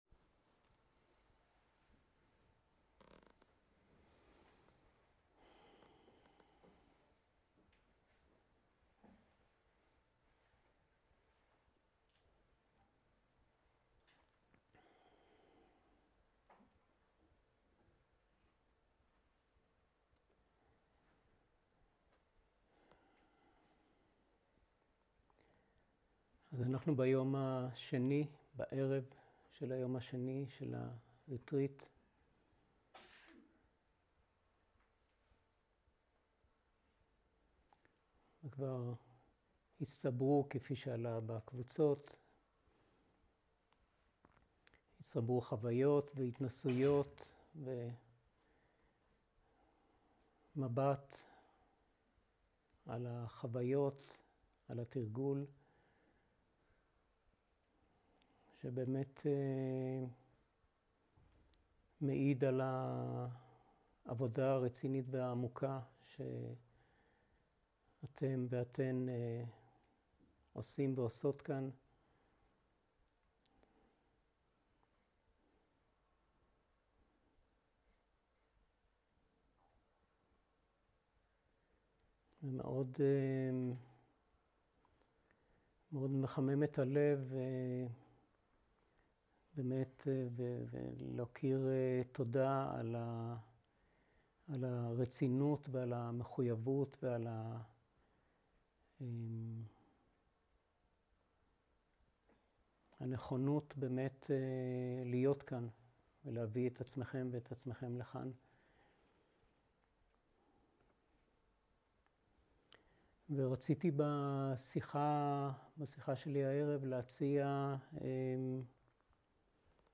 שיחת דהרמה
Dharma type: Dharma Talks שפת ההקלטה